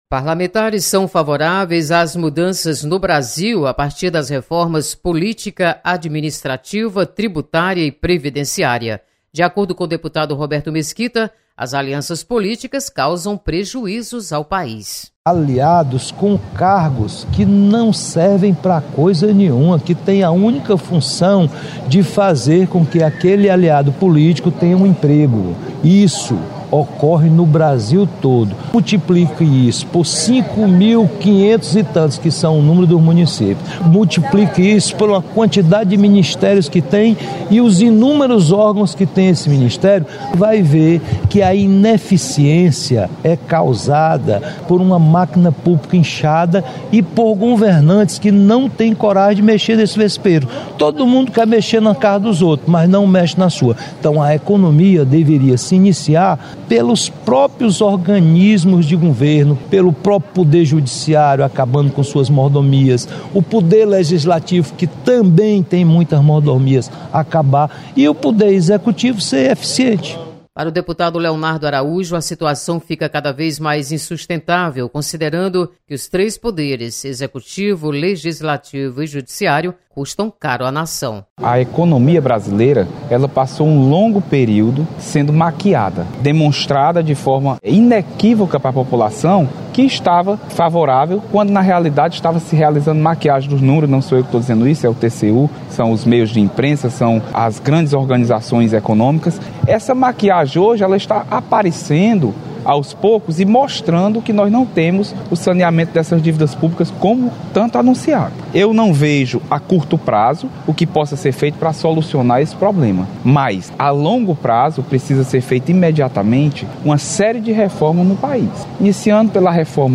Deputados esperam mudanças estruturais com reformas administrativa, tributária, política e previdenciária. Repórter